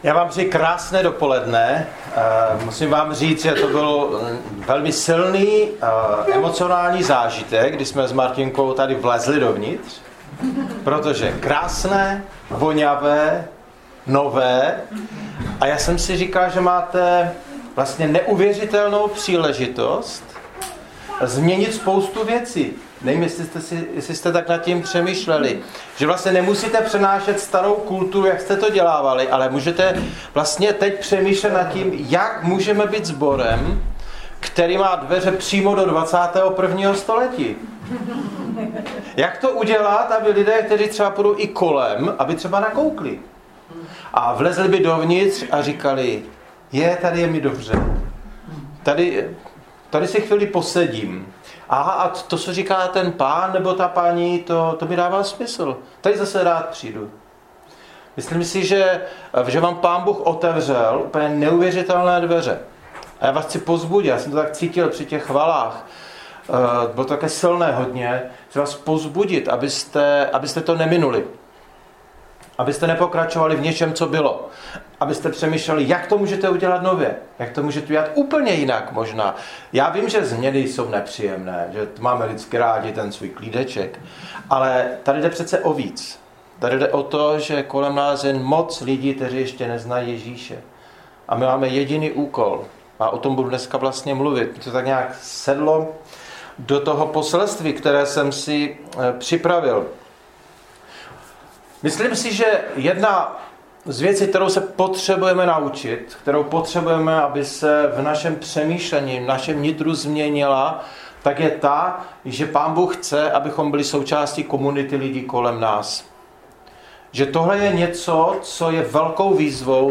kazani